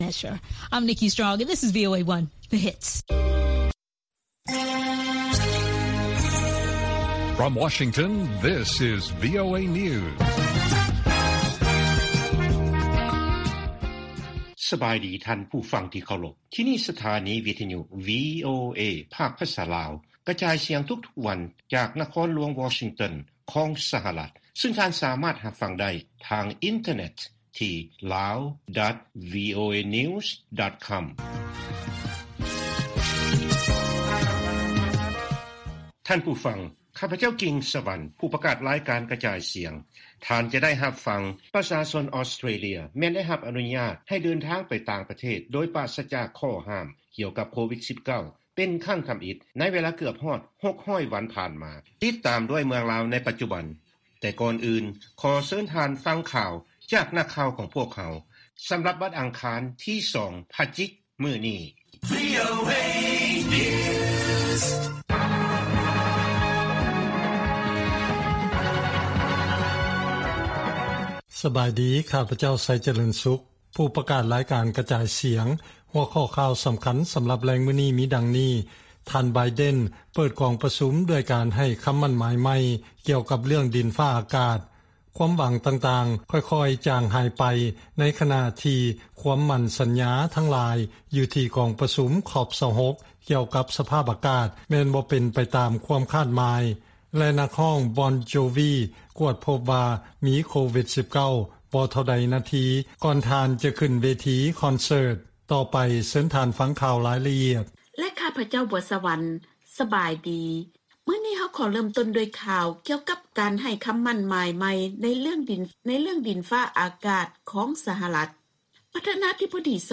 ລາຍການກະຈາຍສຽງຂອງວີໂອເອ ລາວ: ການເກັບລາຍຮັບຂອງລັດຖະບານລາວ ຈະຕໍ່າກວ່າແຜນເຖິງ 14 ເປີເຊັນ ໃນແຜນງົບປະມານຂອງປີ 2021